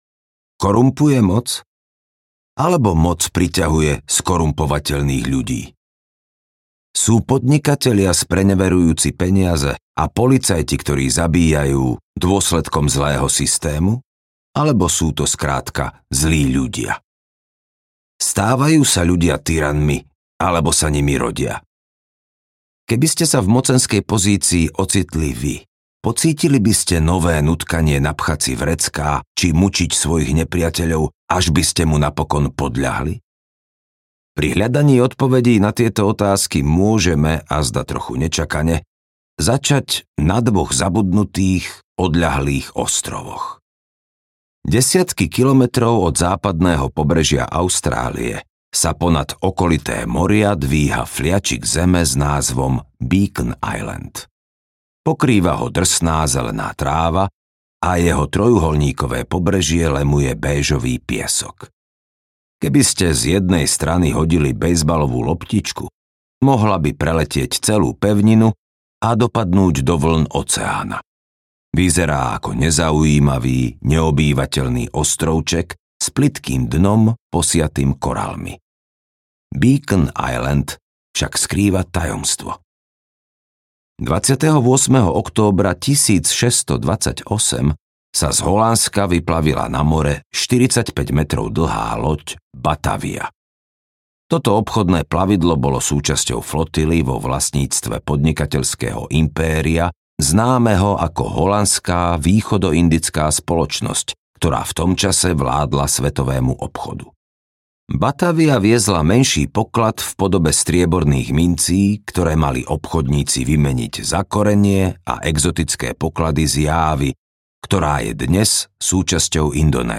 audiokniha